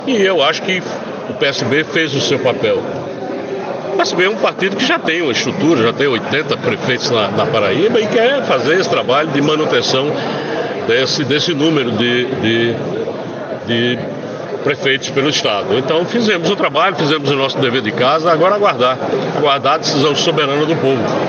O comentário do gestor foi registrado pelo programa Correio Debate, da 98 FM, de João Pessoa, nesta segunda-feira (08/04).